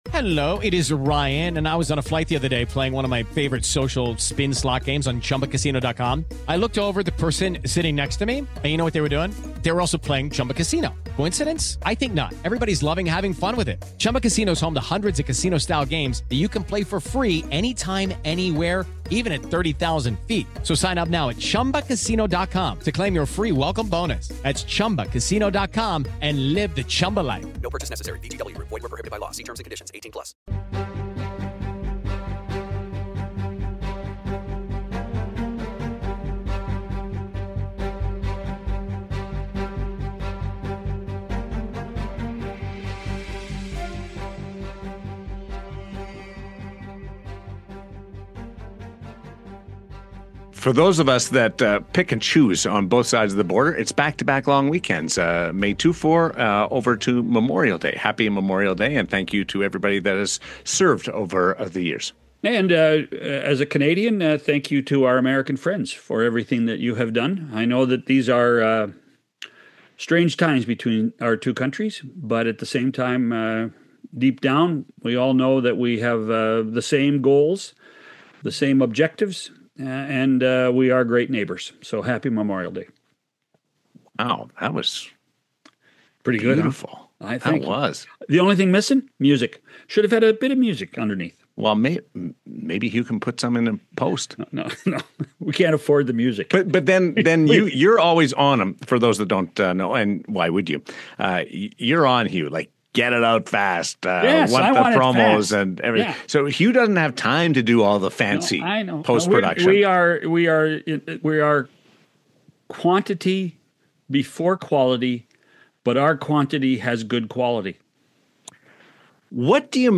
What started as a plan to break down the third round of the playoffs quickly turned into a spirited chat about hockey broadcasting.